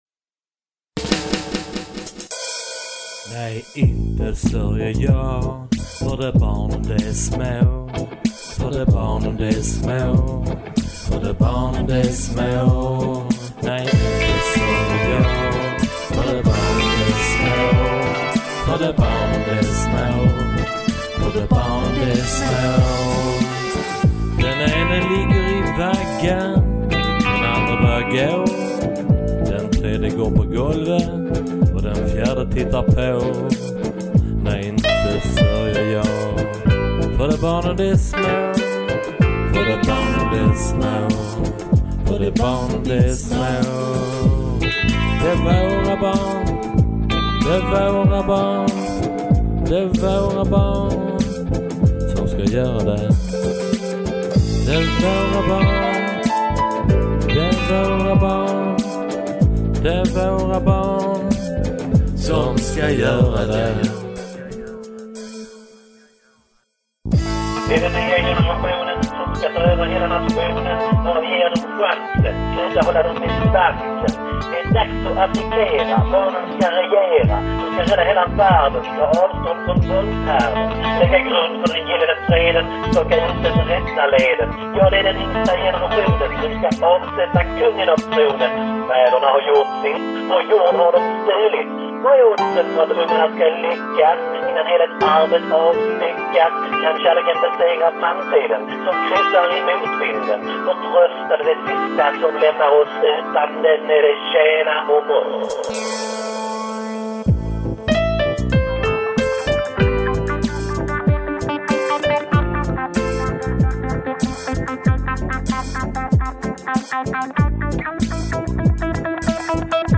All songs are recorded and mixed in their home studios.